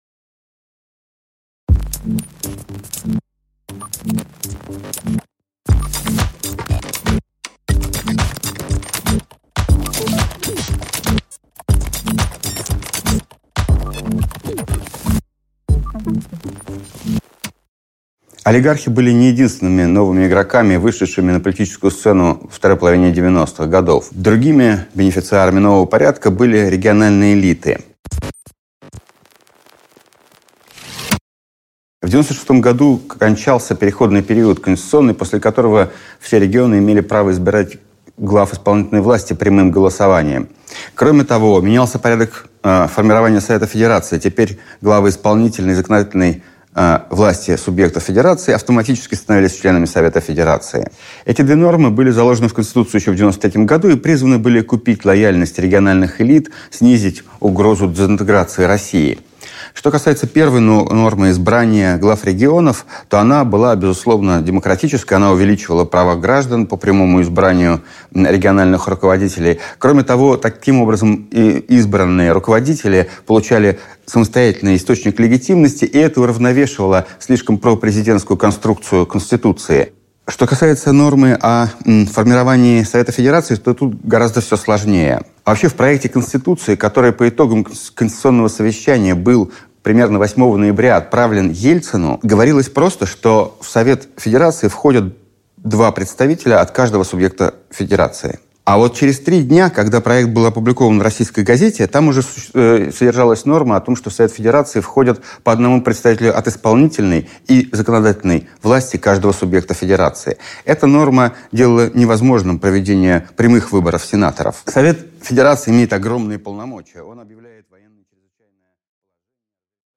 Аудиокнига Политические корпорации | Библиотека аудиокниг